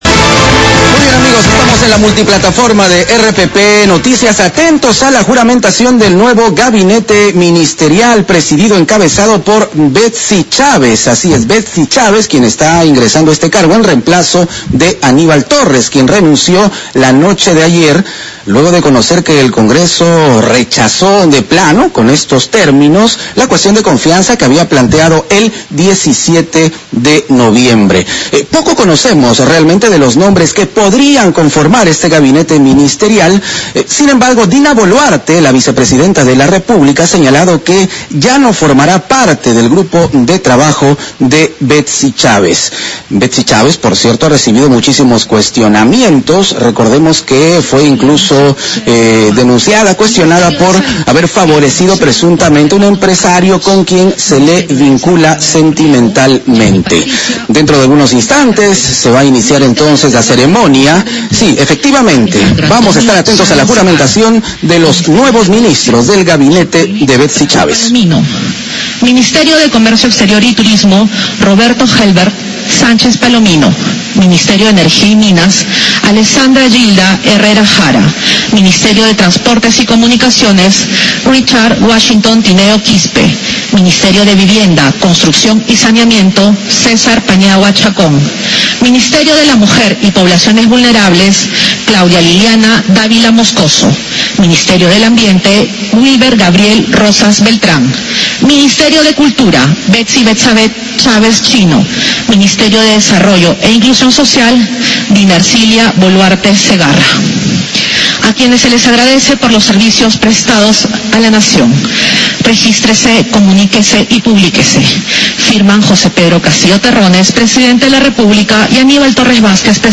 Enlace. El presidente de la República, Pedro Castillo, toma juramento a los ministros que integrarán el gabinete de la premier Betssy Chávez.